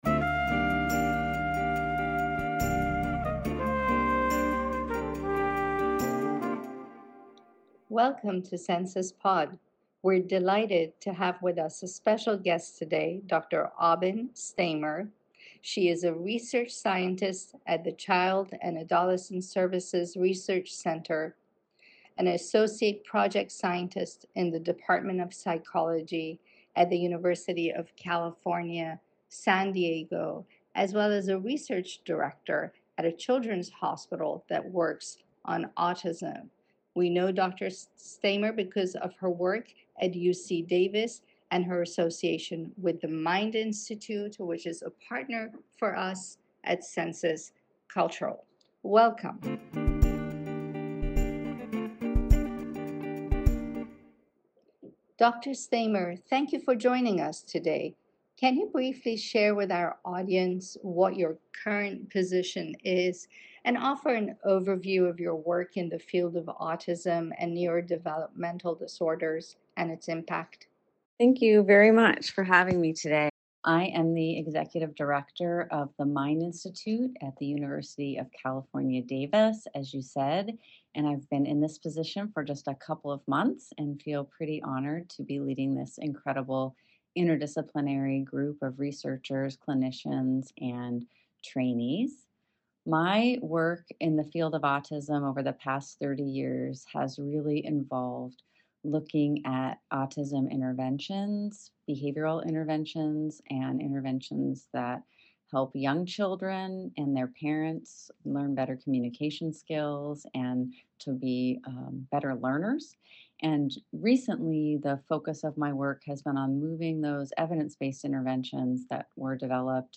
Join us as we explore her inspiring journey, groundbreaking research, and her vision for the future of autism care and support. This is a conversation you won’t want to miss. https